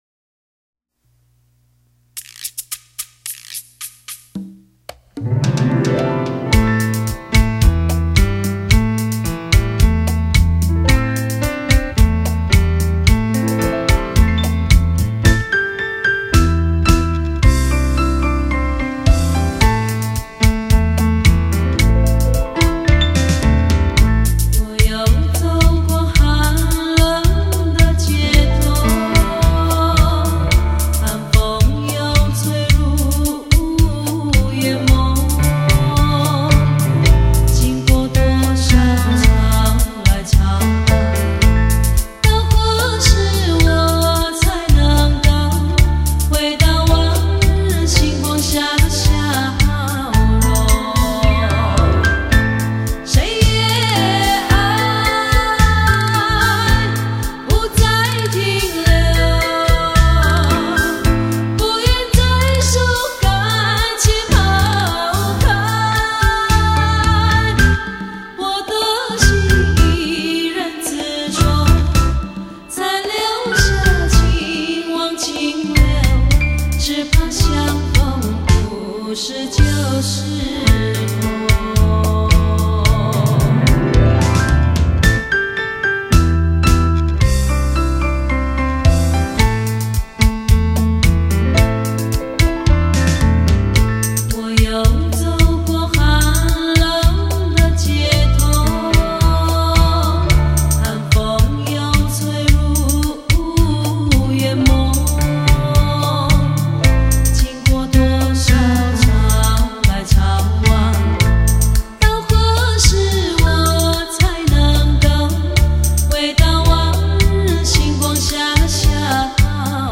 高山族实力派唱将
最原始的旋律